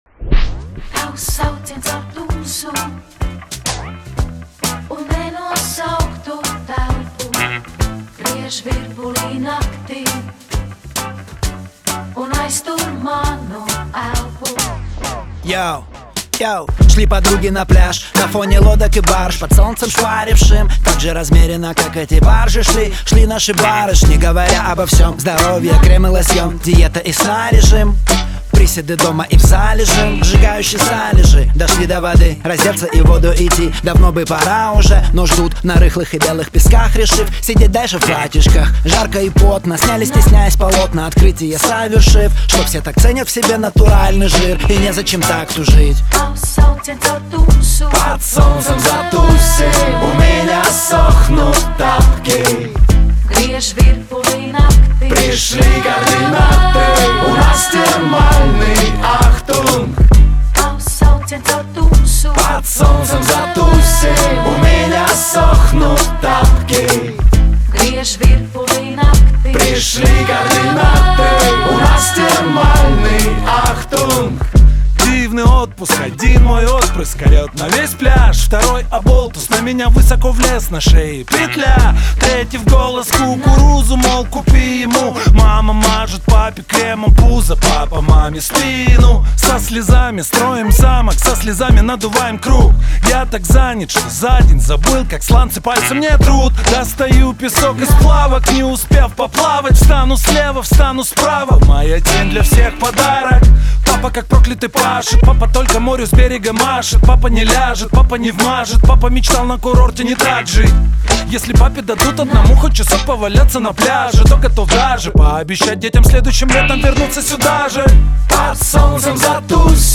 объединяющее в себе элементы хип-хопа и джаза.